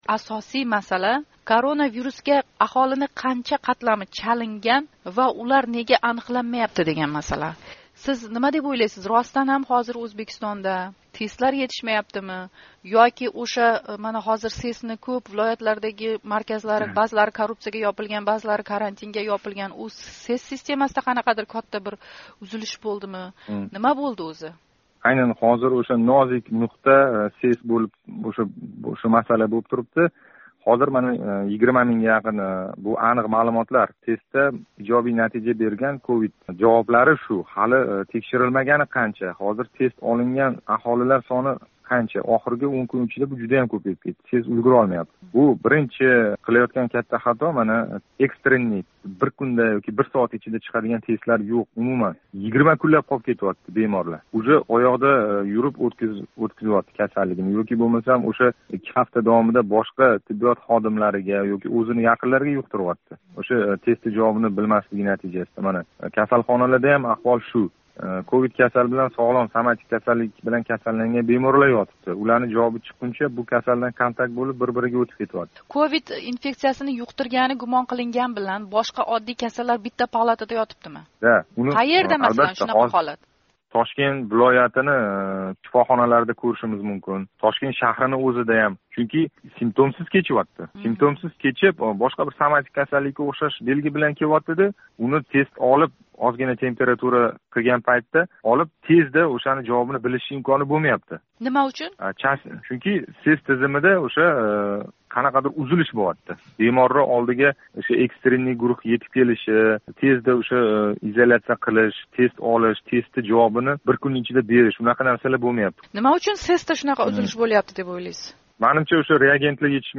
Тошкентлик шифокор билан суҳбат